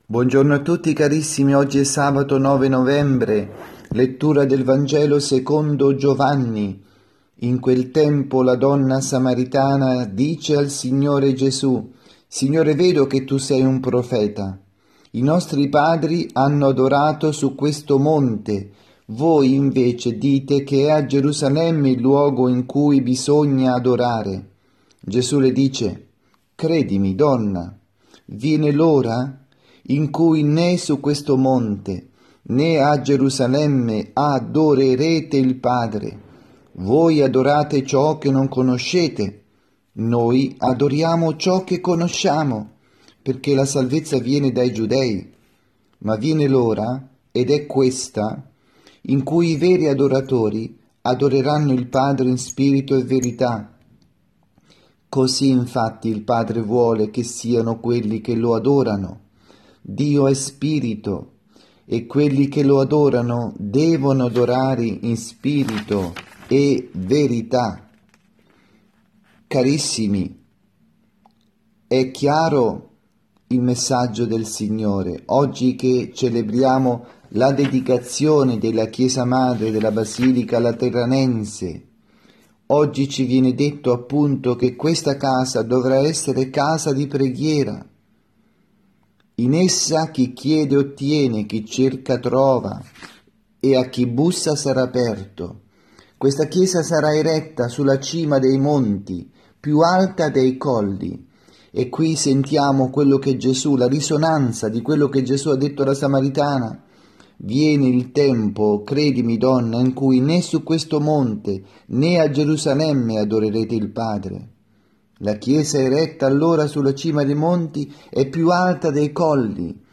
avvisi, Omelie
2019-11-09_Sabato_pMG_Omelia_Messa_Vigiliare_di_Domenica_10_novembre_da_Rossano_Calabro